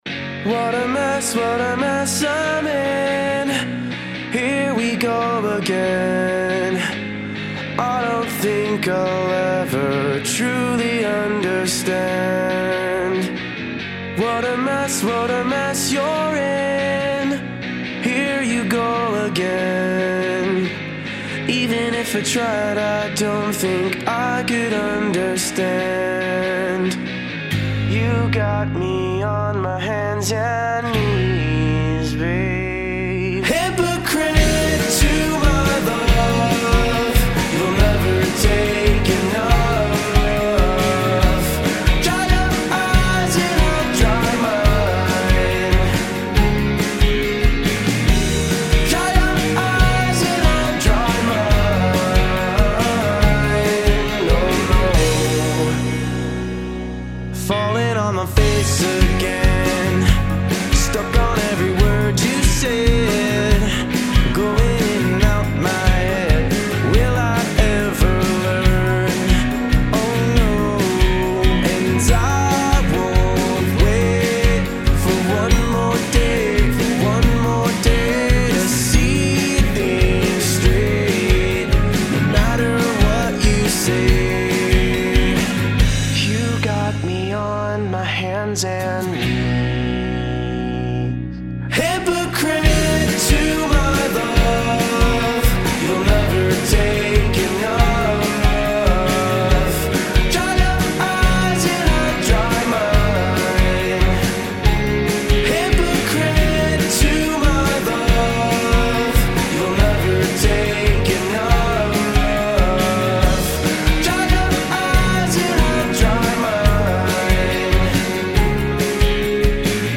Alt/Pop/Rock Producer & Singer-Songwriter